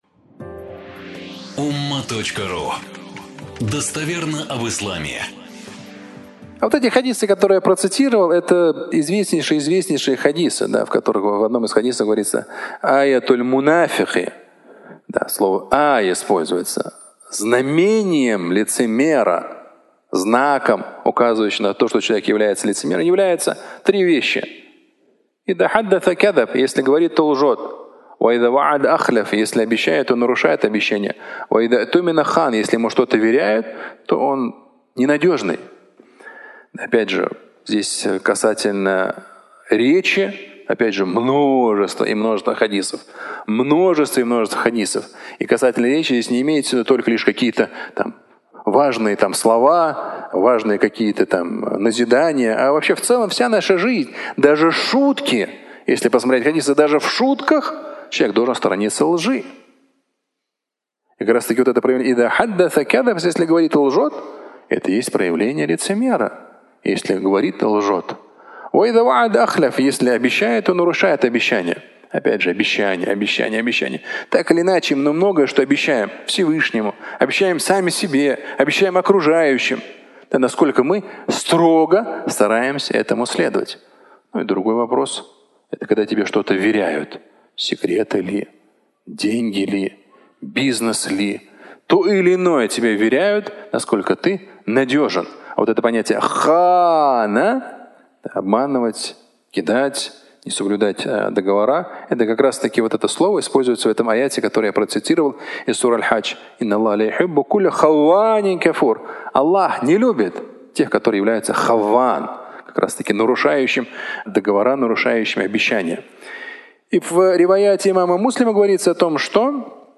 Фрагмент пятничной проповеди